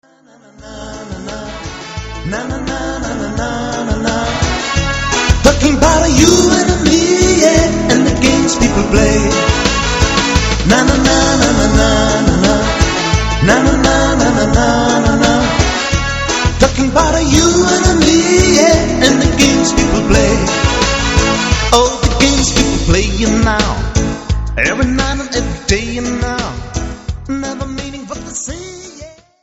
Party-Music-Band
reggae 0:30 Min.